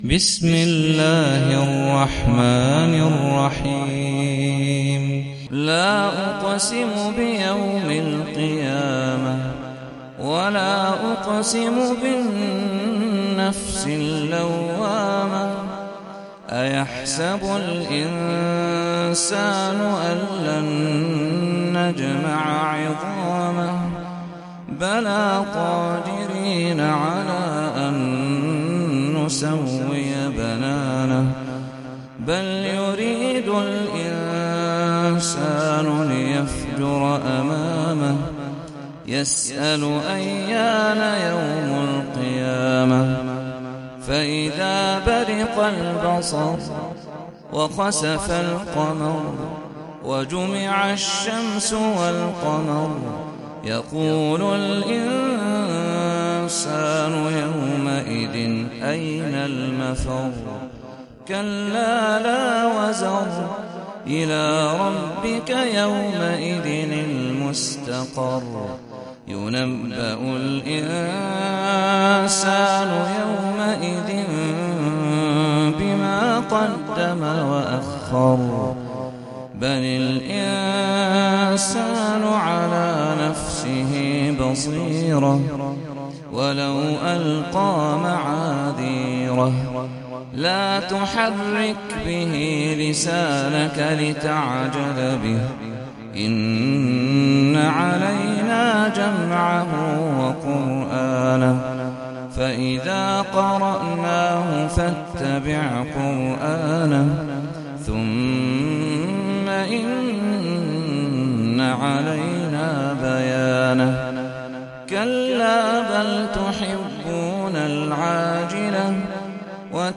سورة القيامة - صلاة التراويح 1446 هـ (برواية حفص عن عاصم)
جودة عالية